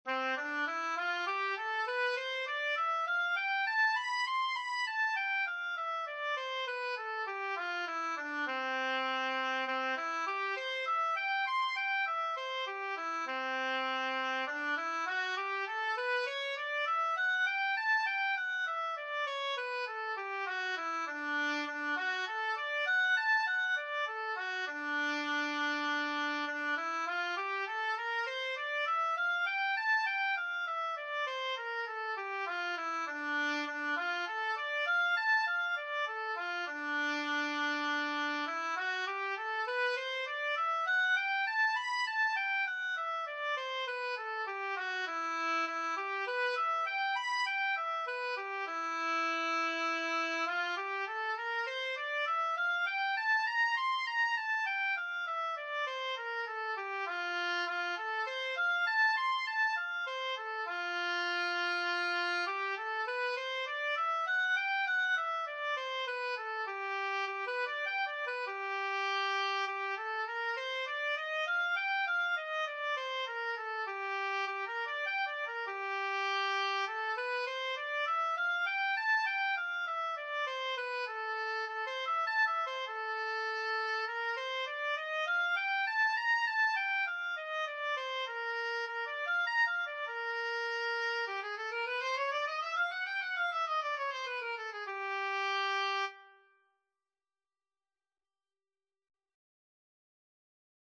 Oboe scales and arpeggios - Grade 3
C major (Sounding Pitch) (View more C major Music for Oboe )
C5-C7
oboe_scales_grade3_OB.mp3